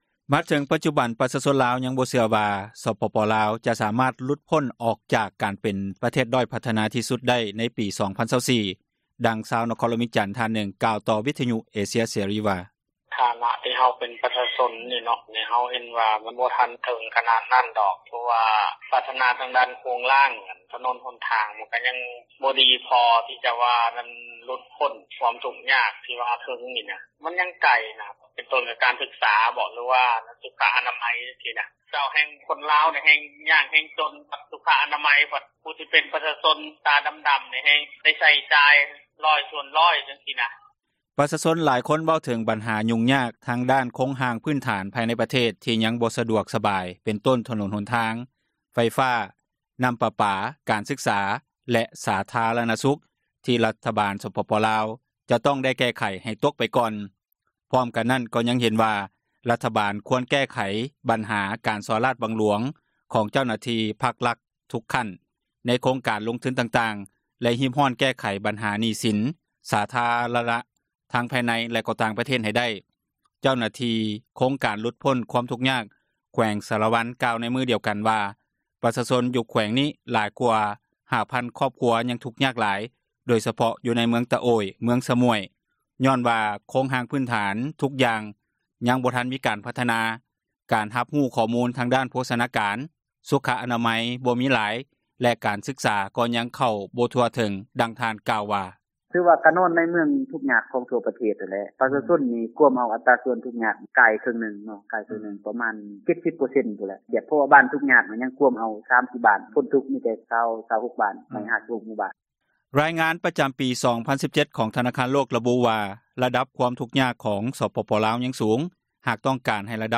ມາເຖິງປັດຈຸບັນ ປະຊາຊົນລາວ ຍັງບໍ່ເຊື່ອວ່າ ສປປລາວ ຈະສາມາດຫລຸດພົ້ນອອກຈາກການເປັນປະເທສ ດ້ອຍພັທນາ ທີ່ສຸດໄດ້ ໃນປີ 2024, ດັ່ງຊາວນະຄອນຫລວງວຽງຈັນ ທ່ານນຶ່ງ ກ່າວຕໍ່ວິທຍຸເອເຊັຍເສຣີ ວ່າ: